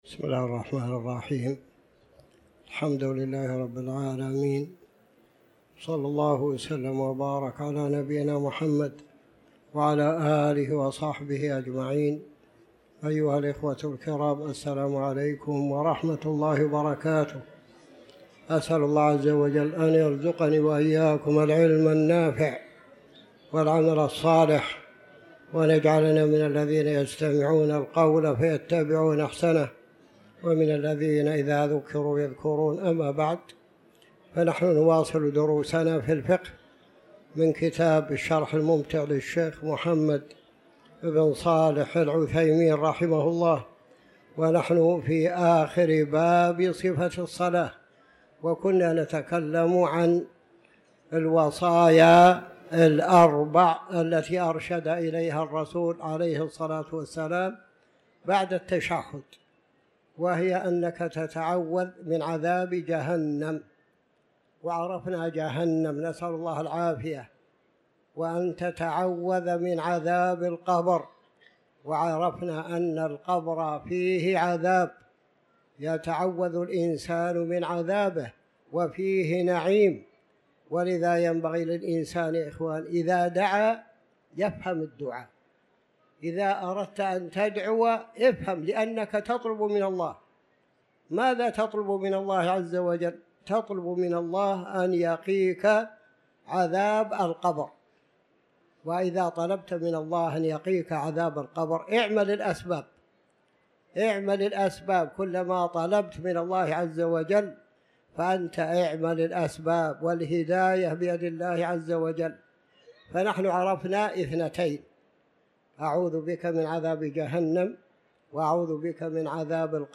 تاريخ النشر ١٨ ذو القعدة ١٤٤٠ هـ المكان: المسجد الحرام الشيخ